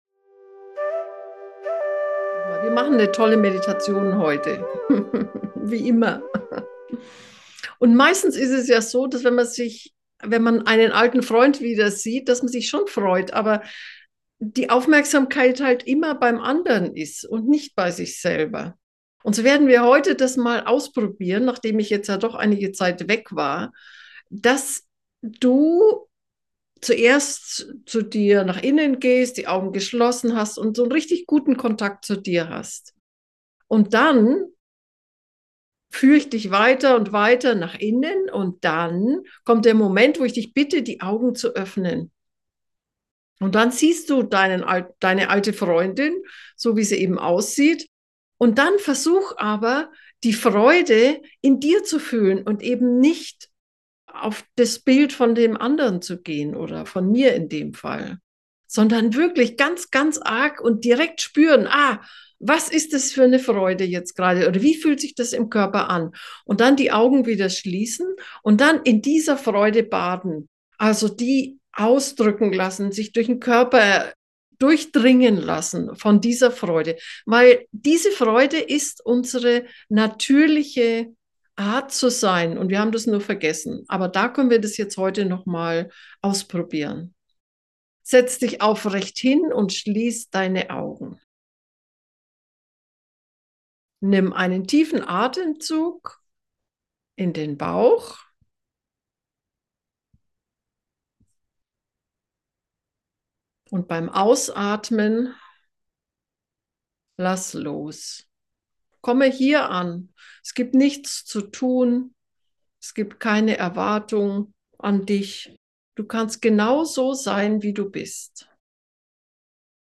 Diese geführte, tantrische Meditation ist für den Alltag geeignet.